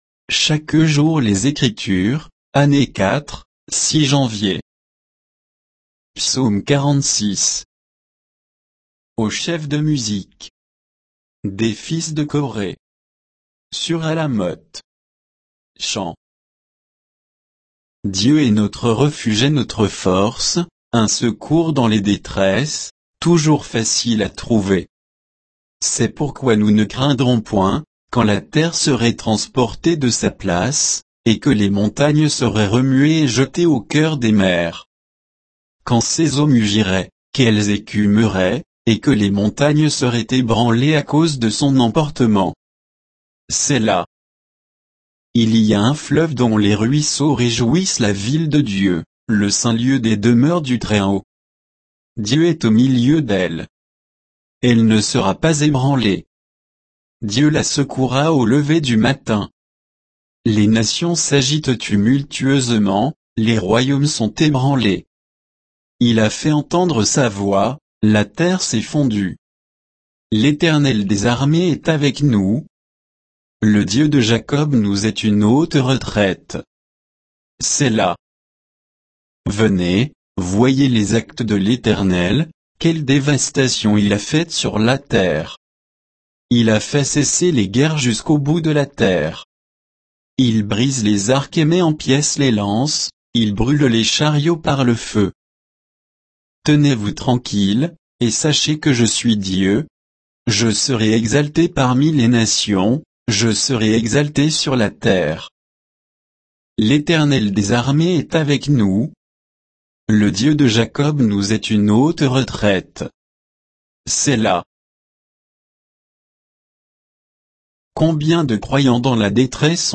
Méditation quoditienne de Chaque jour les Écritures sur Psaume 46